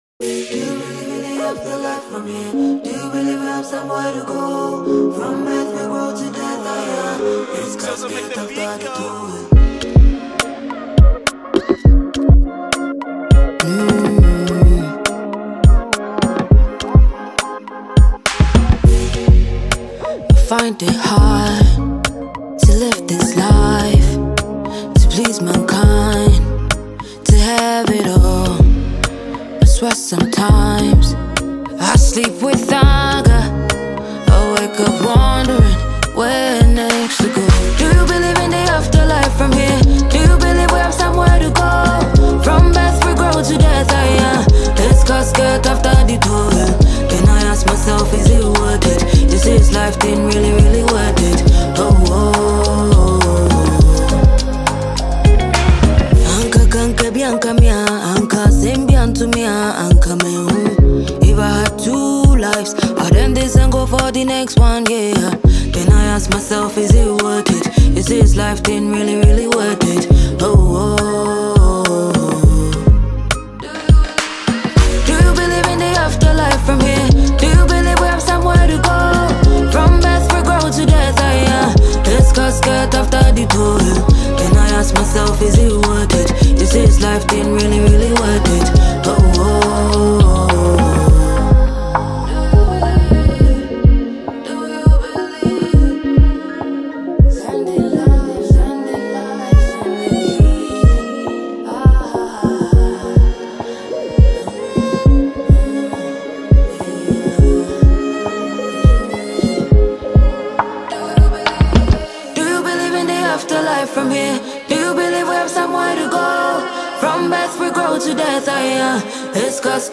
high-tension track